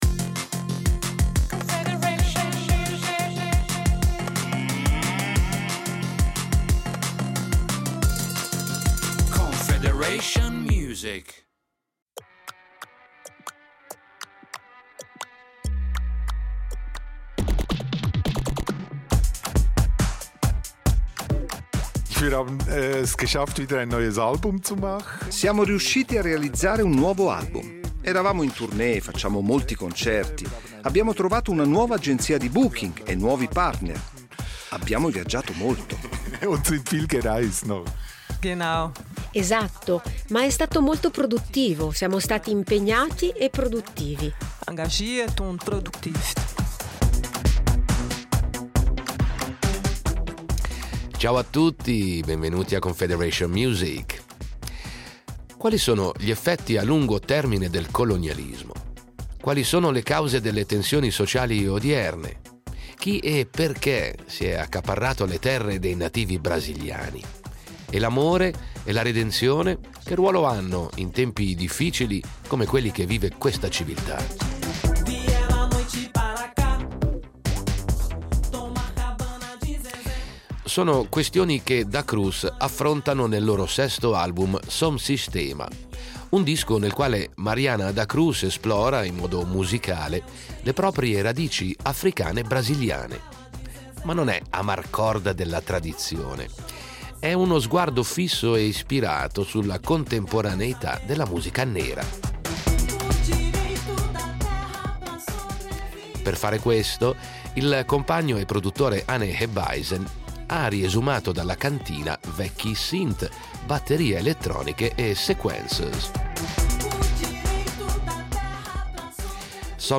Musica elettronica
È musica clubby nera che fa muovere il corpo e il cervello.